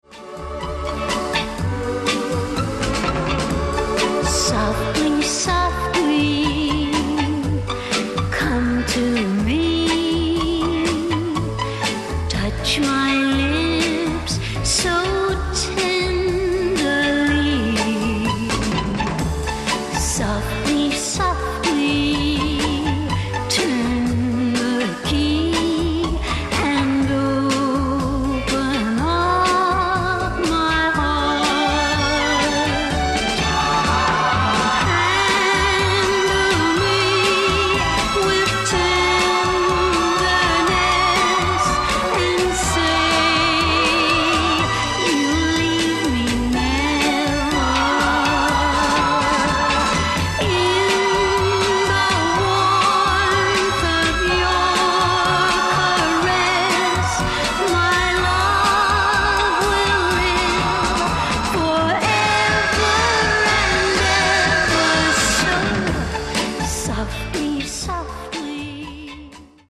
upbeat version